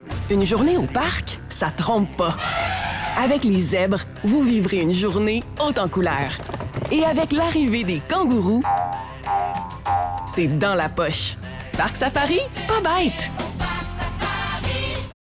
publicité radio